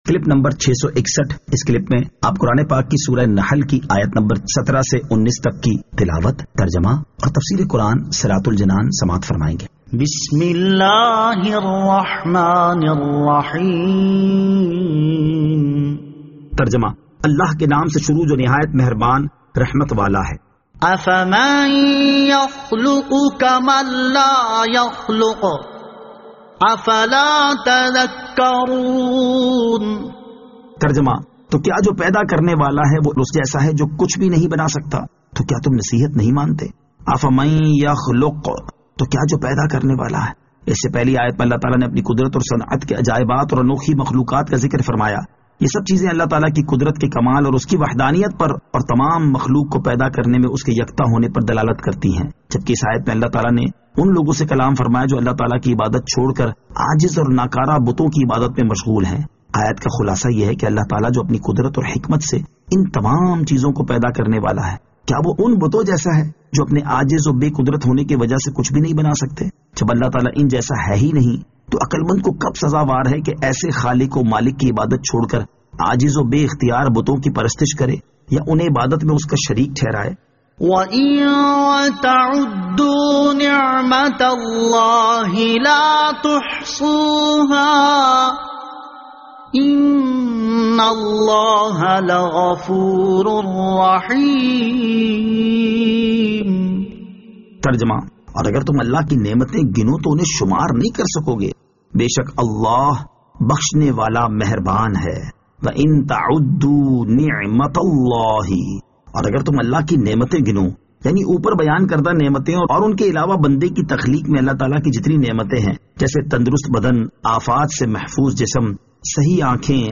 Surah An-Nahl Ayat 17 To 19 Tilawat , Tarjama , Tafseer